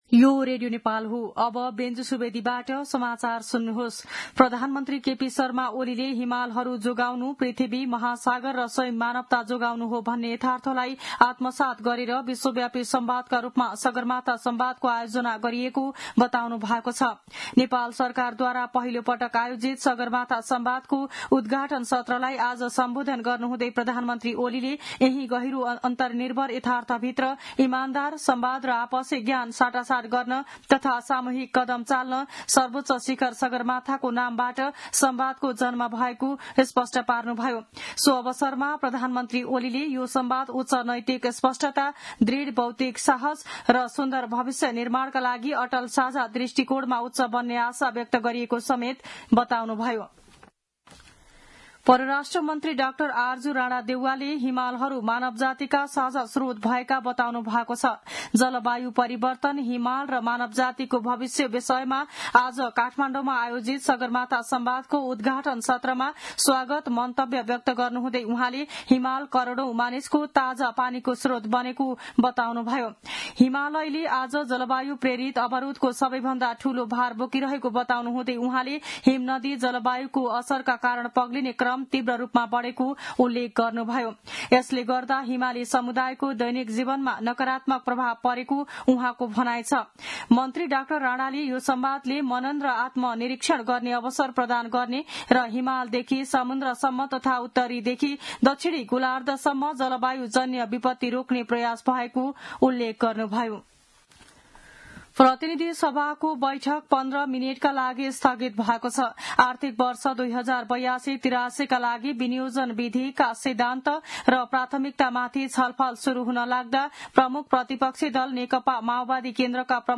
मध्यान्ह १२ बजेको नेपाली समाचार : २ जेठ , २०८२